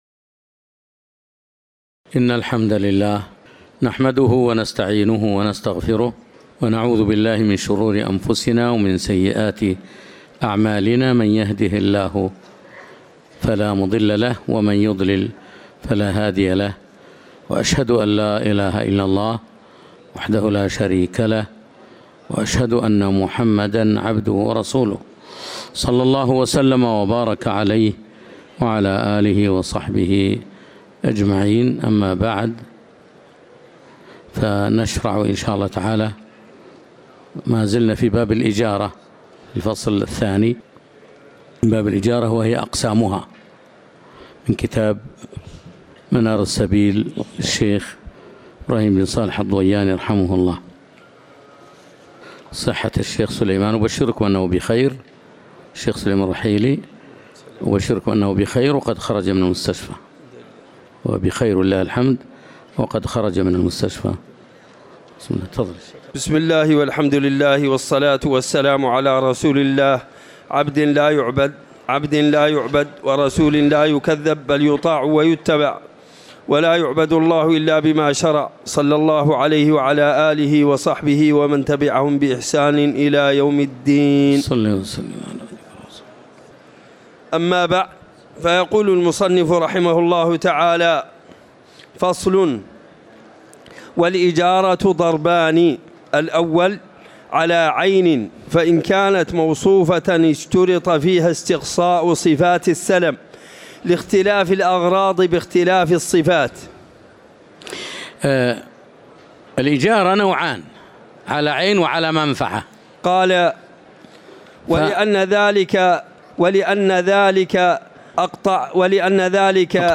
تاريخ النشر ١٨ جمادى الآخرة ١٤٤١ هـ المكان: المسجد النبوي الشيخ